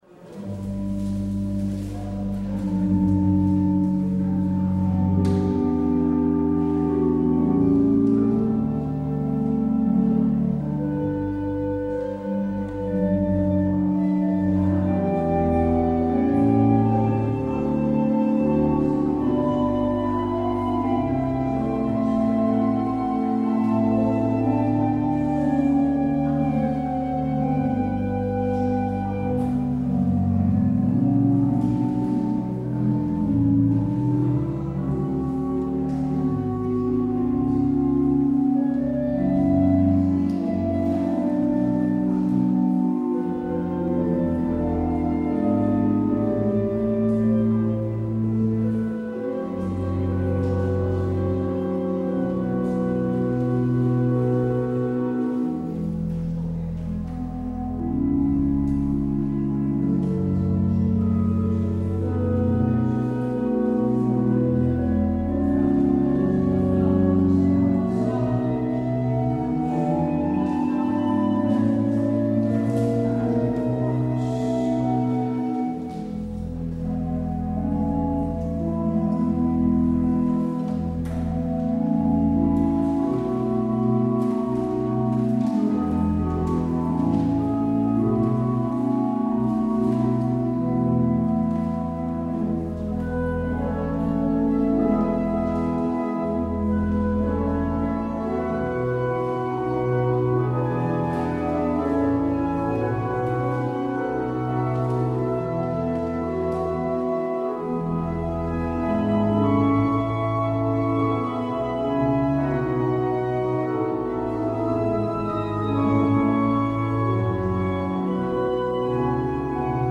 Luister deze kerkdienst hier terug
Het openingslied is: Lied 900, Nada te turbe (4x).